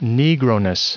Prononciation du mot negroness en anglais (fichier audio)
Prononciation du mot : negroness